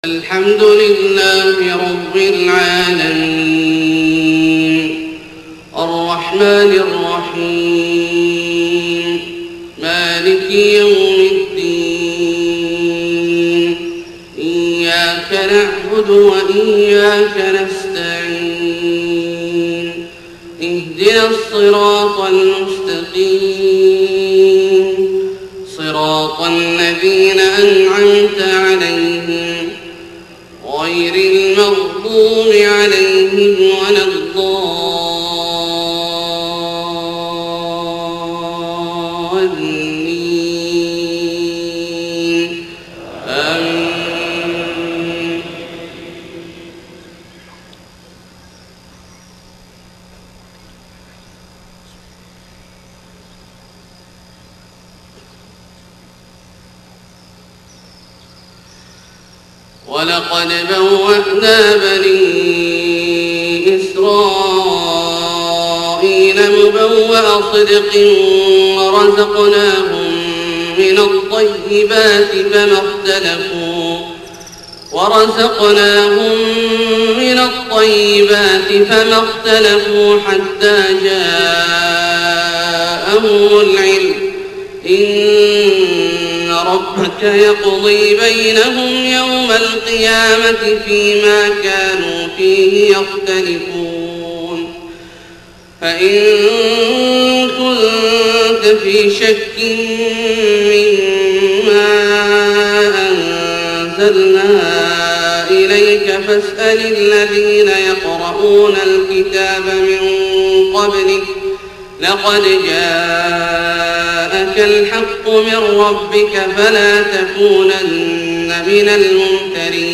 صلاة الفجر 12 ربيع الأول 1431هـ من سورة يونس {93-109} > 1431 🕋 > الفروض - تلاوات الحرمين